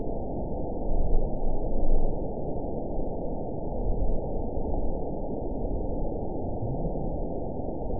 event 919845 date 01/25/24 time 23:16:44 GMT (1 year, 3 months ago) score 8.69 location TSS-AB01 detected by nrw target species NRW annotations +NRW Spectrogram: Frequency (kHz) vs. Time (s) audio not available .wav